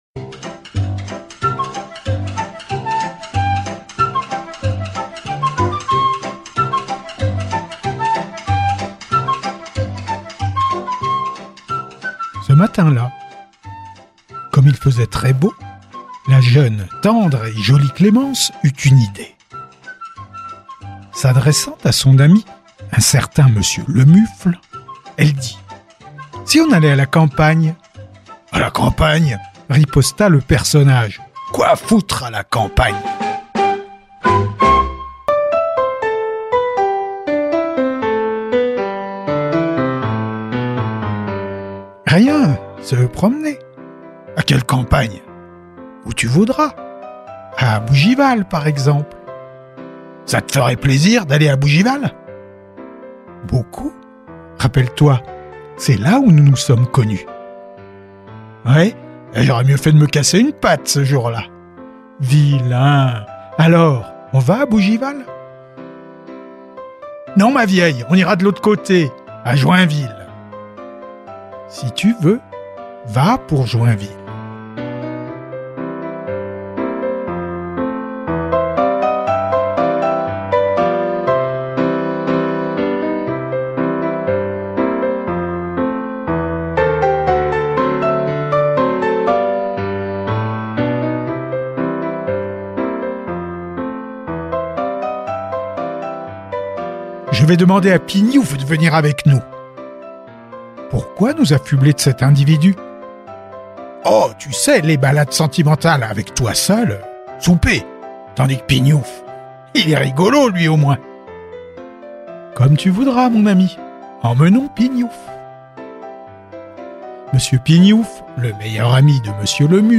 Chronique & texte (17:00)